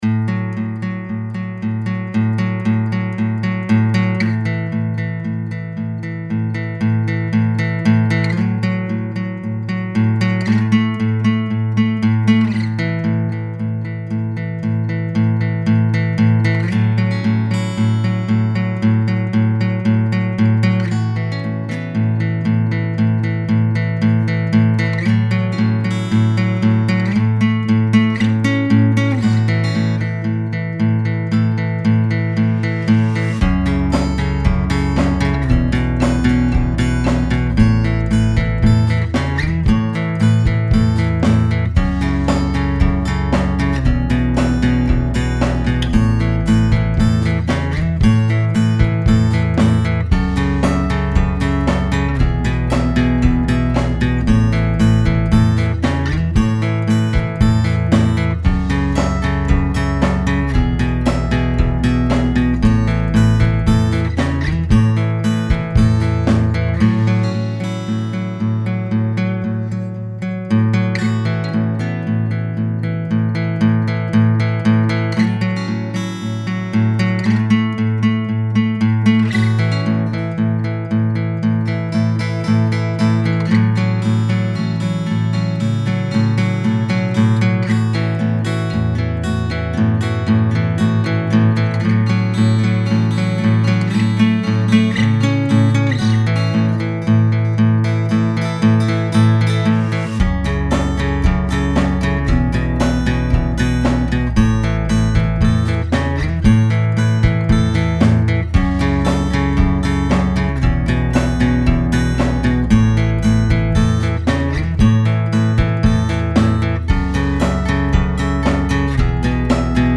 a folk song with happy vibes
115 BPM
folk happy upbeat uplifting acoustic guitar drums